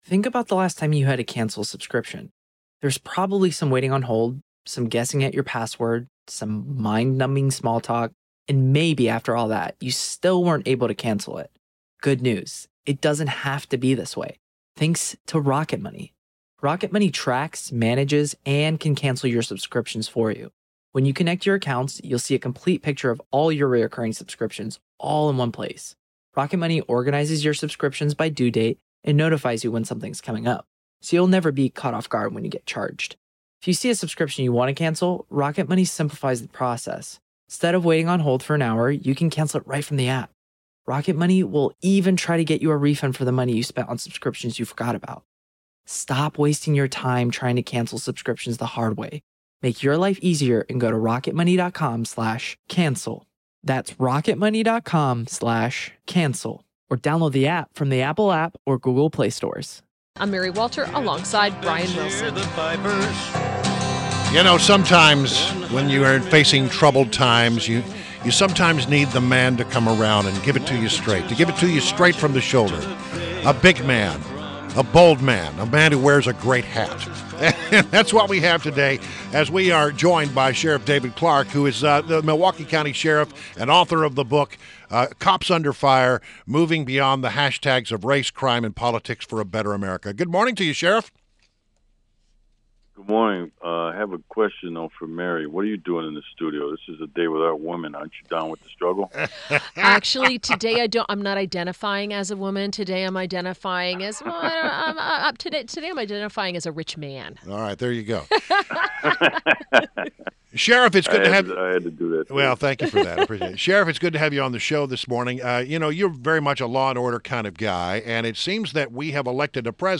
WMAL Interview - SHERIFF DAVID CLARKE - 03.08.17